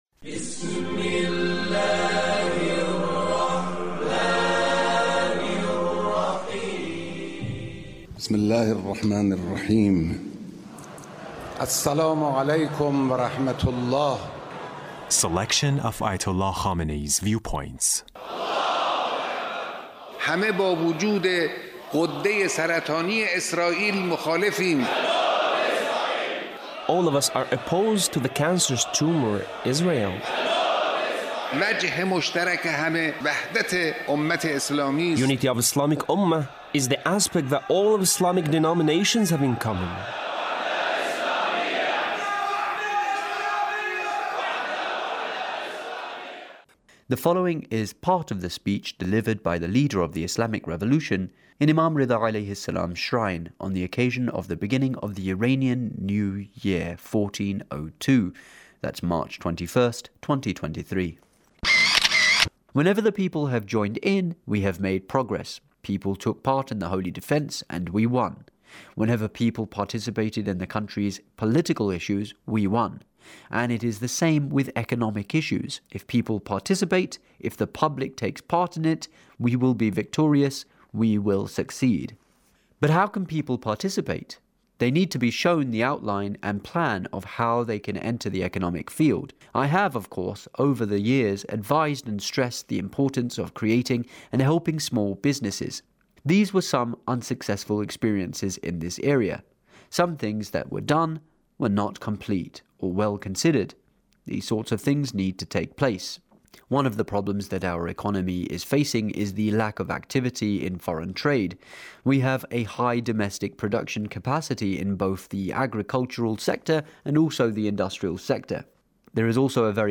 Leader's Speech on Norooz 1402 on Economy